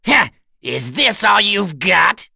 Bernheim-Vox_Dead_z.wav